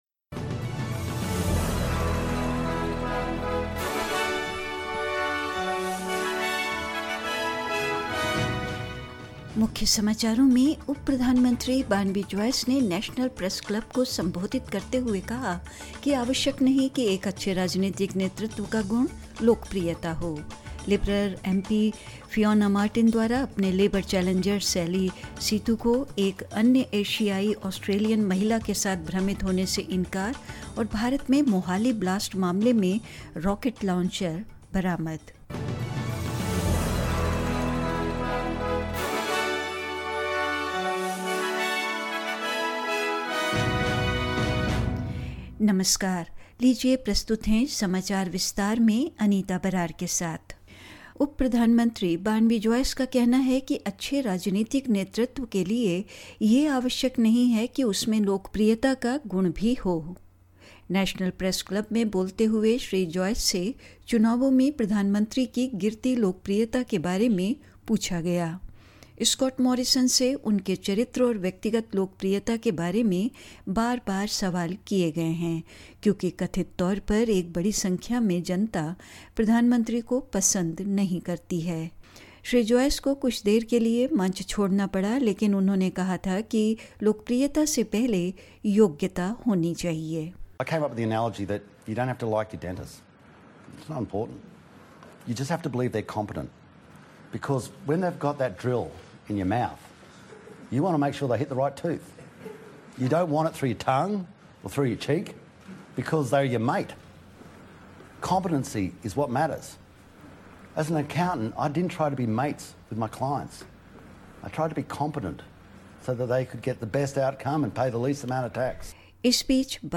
In this latest SBS Hindi bulletin: Deputy Prime Minister Barnaby Joyce says popularity is not necessarily an attribute of good political leadership; Liberal M-P Fiona Martin denies confusing her Labor challenger Sally Sitou, with another Asian-Australian woman; In India, Police recovered the rocket launcher used in Mohali blast and more news.